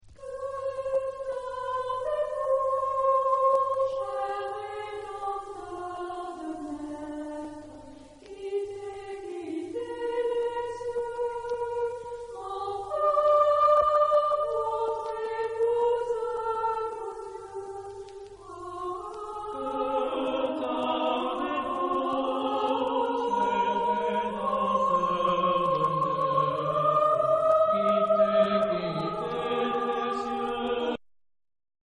Genre-Style-Forme : noël ; contemporain
Type de choeur : SATB  (4 voix mixtes )
Tonalité : sol mineur